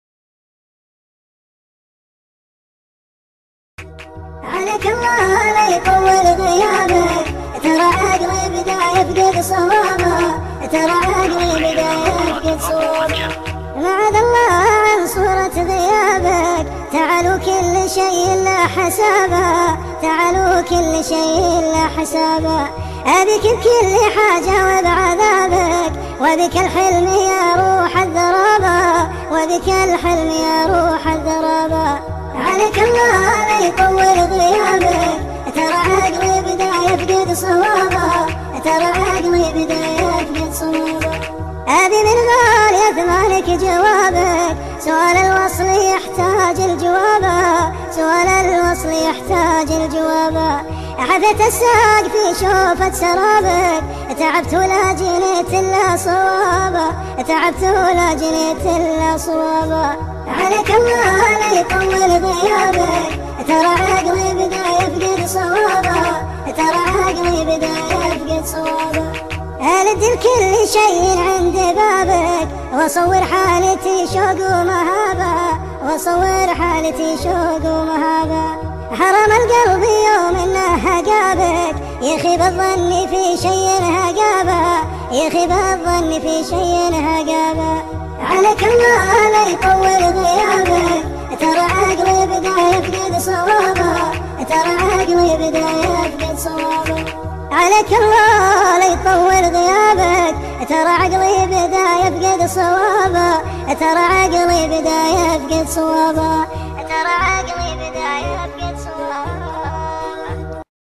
مسرع